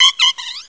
Cri de Nanméouïe dans Pokémon Noir et Blanc.